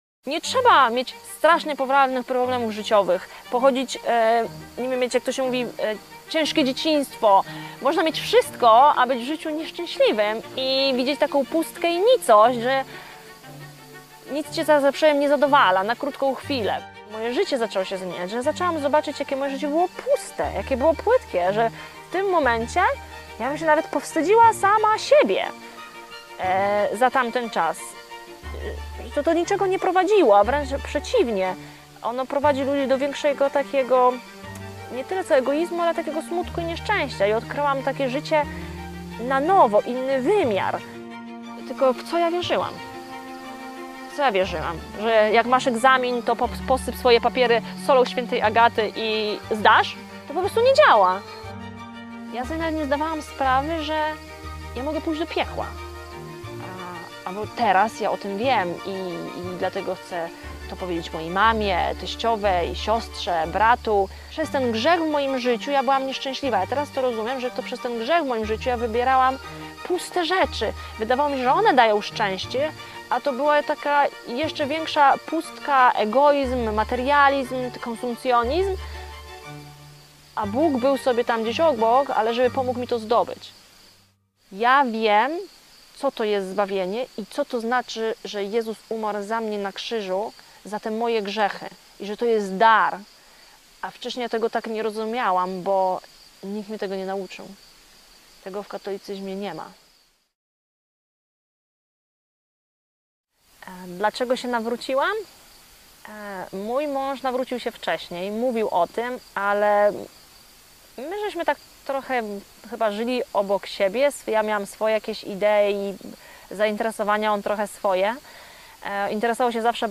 świadectwo